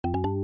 pop-up-notification.mp3